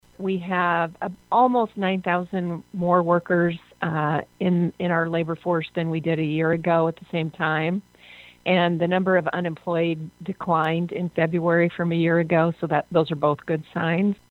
TOWNSEND SAYS THERE ARE ALSO POSITIVES IN THE JOB MARKET.